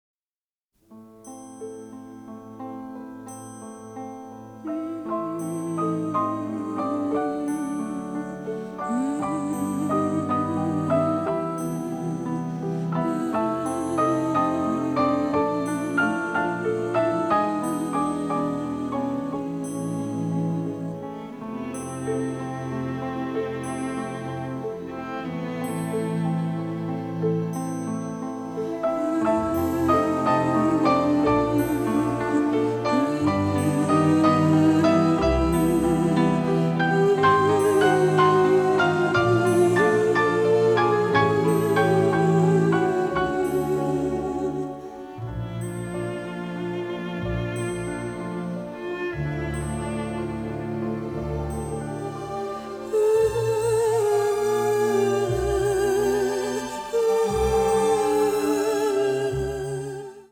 giallo score